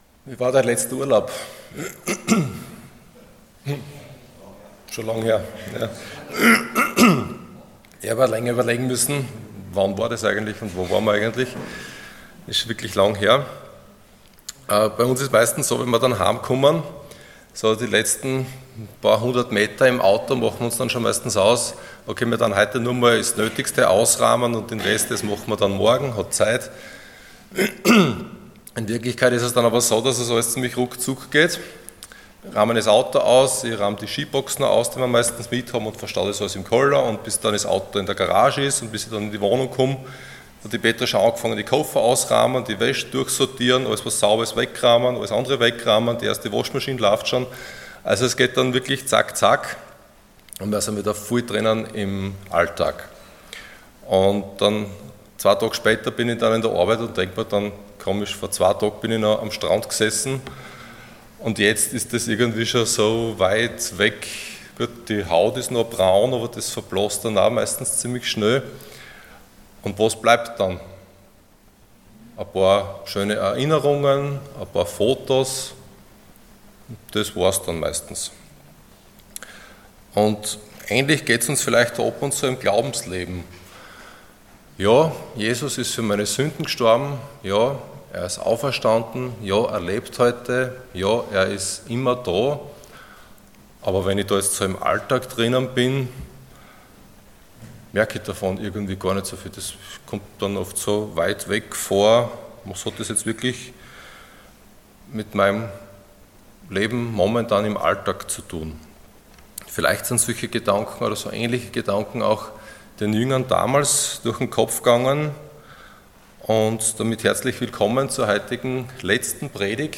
Passage: John 21:1-14 Dienstart: Sonntag Morgen